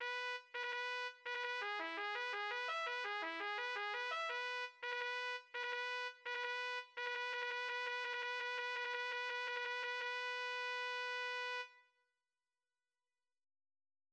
Фанфара (от фр. fanfare; в английском языке — fanfare или flourish; в немецком — Fanfare) — это музыкальная тема яркого, блестящего характера[1], исполняемая медными духовыми инструментами в специальных ансамблях, которые также называются фанфарами.
Фанфара, открывающая знаменитый Allegro vivace в увертюре к опере Гильом Телль. Тональность — ми мажор (ми–соль♯–си).